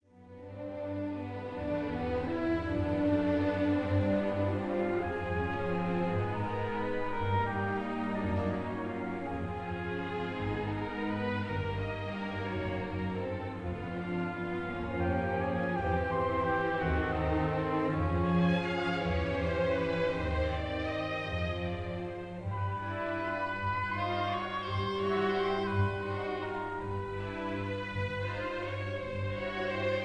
Allegro comodo e flemmatico (Phlegmatic)
1956 live performance